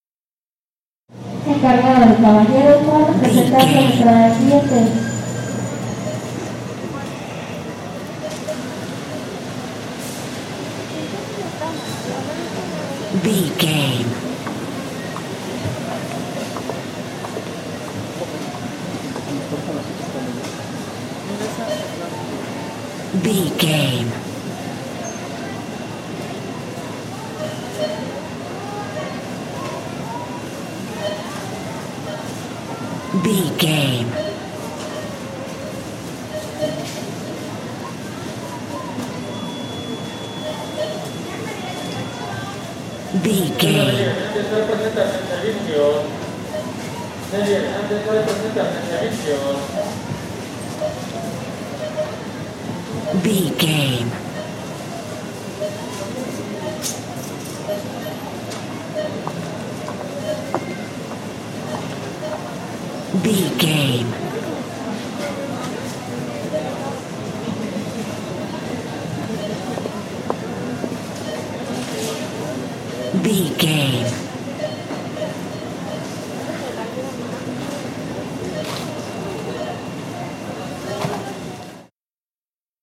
Supermarket ambience cashier
Sound Effects
backgrounds
ambience